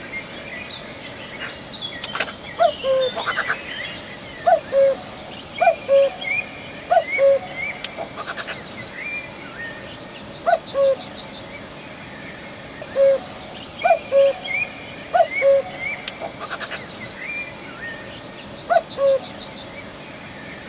布谷鸟叫声
四声杜鹃鸟独特的四声叫声